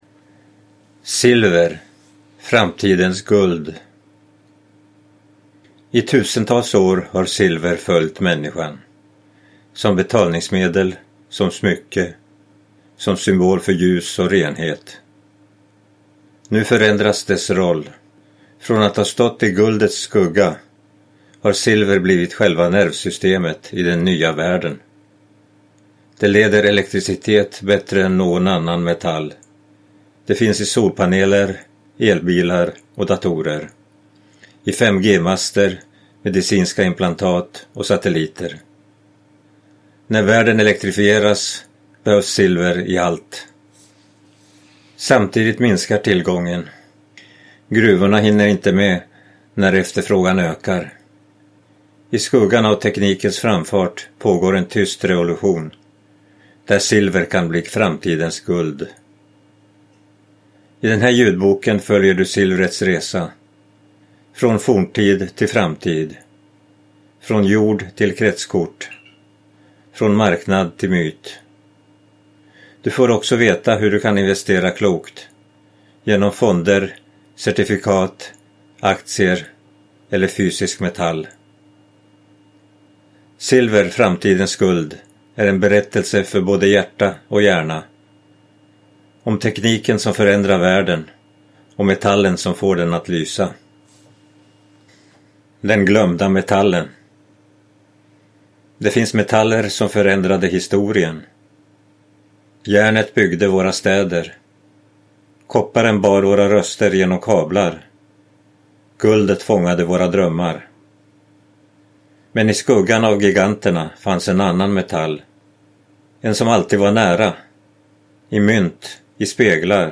SILVER – Bristvaran som kan få världen att slockna - Hur du kan bli rik på metallen som världen inte klarar sig utan (ljudbok) av Lars Andersson